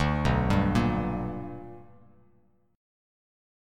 BmM7#5 chord